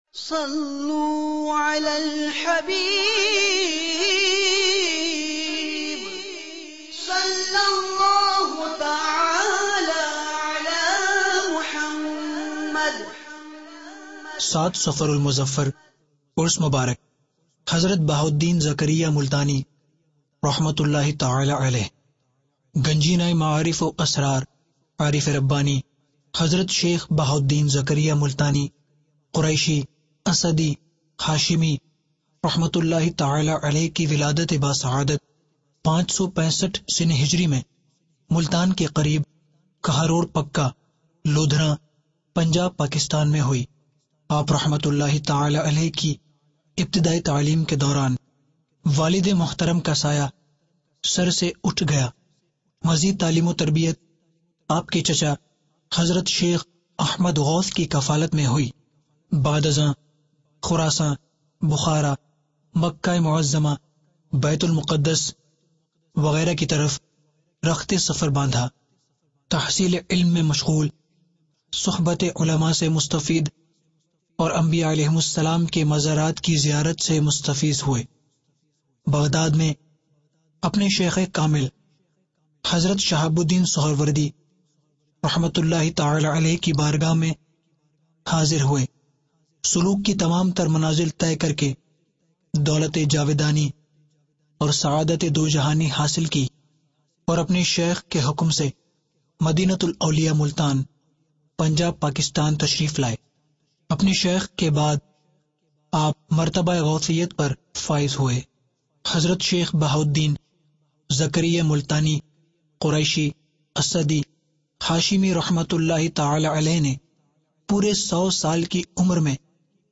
Documentary - Faizan e Hazrat Ghaus Baha Uddin Zakariya Multani Qureshi Asadi Hashimi(2) (رحمۃ اللہ علیہ) Dec 12, 2013 MP3 MP4 MP3 Share ڈاکیومنٹیری ۔